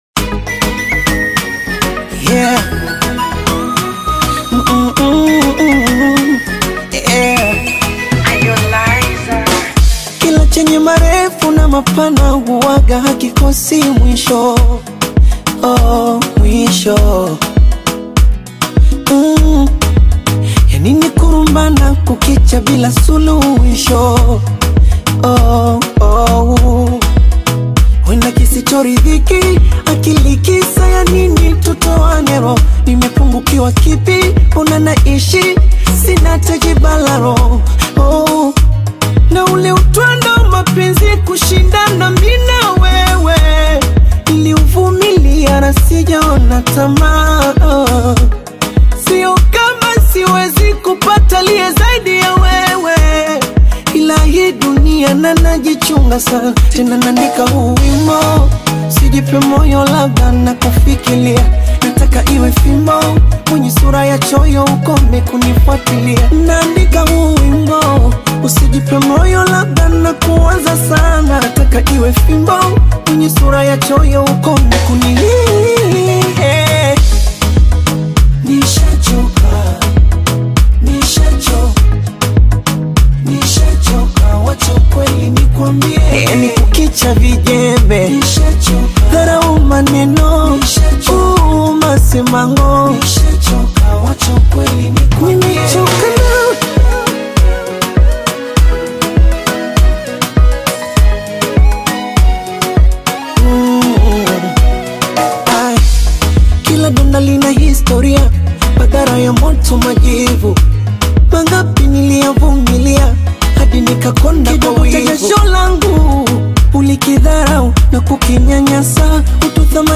is a deeply emotional Bongo Flava single